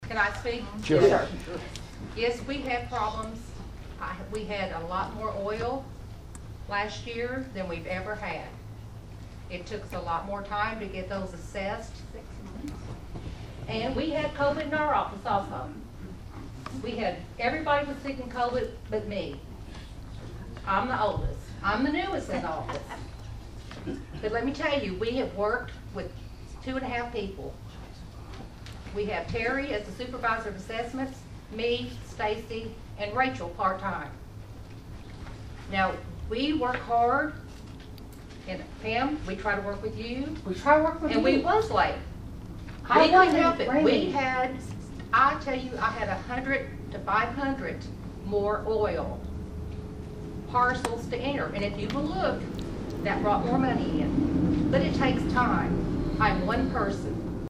Over the next hour, only one individual abided by that request and it was mostly a group conversation that resembled Great Britain’s parliamentary procedures with interruptions and occasional raised voices.
That turned into a melee of comments during which you can’t identify every individual speaking.